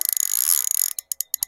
ethereal_casting_rod.ogg